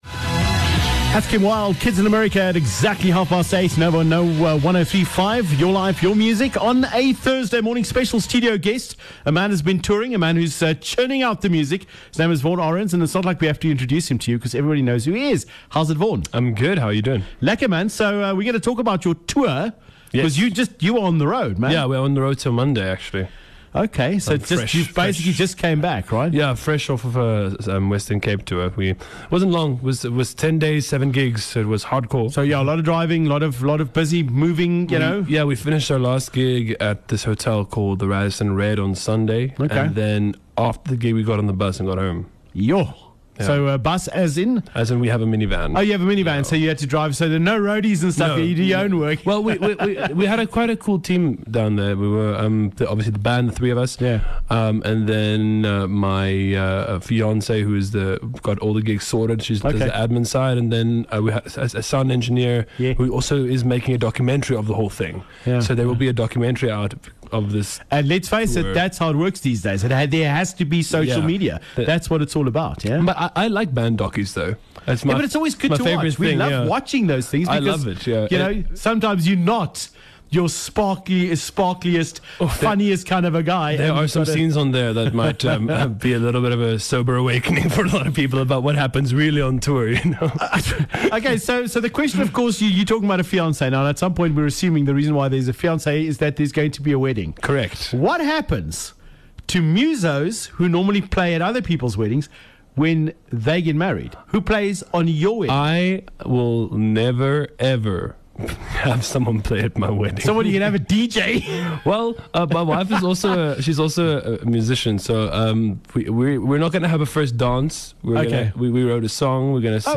pops by the studio